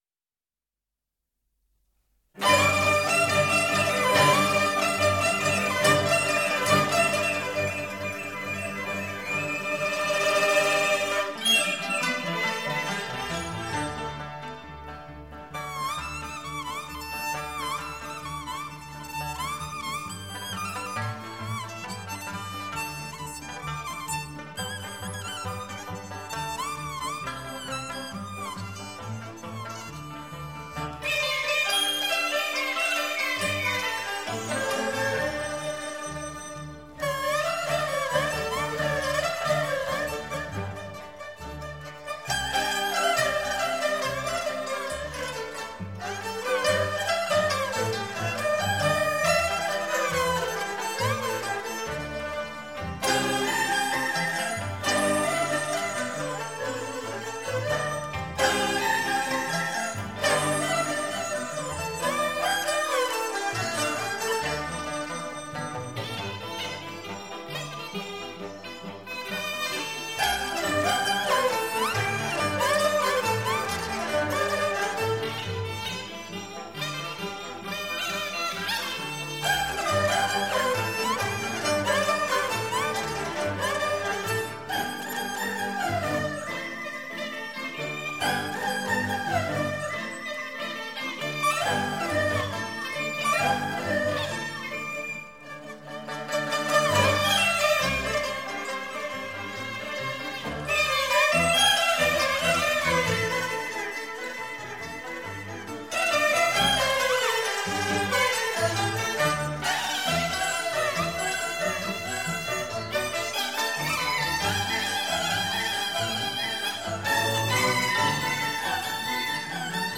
中国民族管弦乐及小品